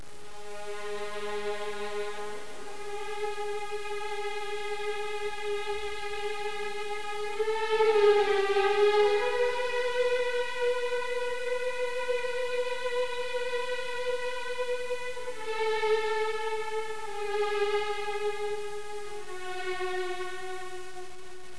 なお、音質は著作権への配慮で、ノイズを付加したものです。
序奏(466K)は前楽章の中間部の動機に結びついているのみでなく、ブルックナーの第９交響曲の最終（第３）楽章の始まりを連想させます。